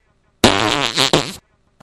FART 19
fart flatulation flatulence gas noise poot sound effect free sound royalty free Memes